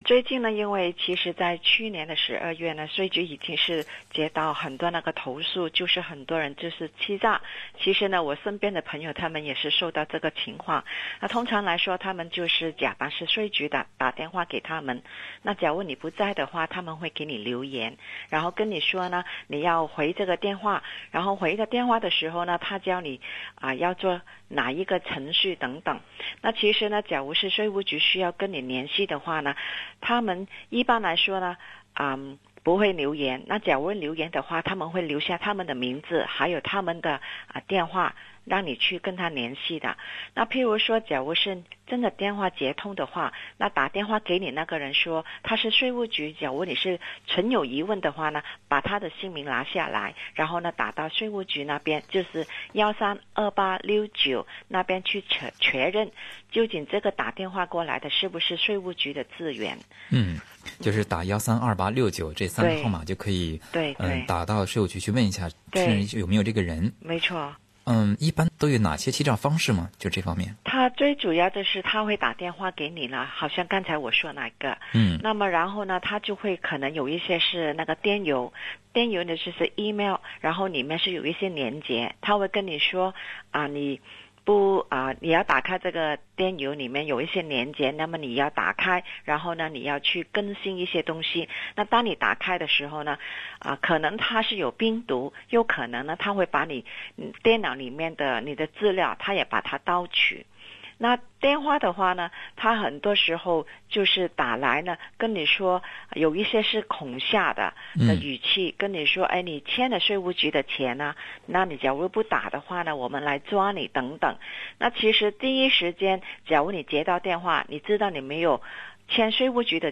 澳大利亚税务局(ATO)提醒大家要时刻保持警觉，因为在过去的几个月里大约有1万名受欺诈个案向ATO的熱线中心投案。 本期《税务知识讲座》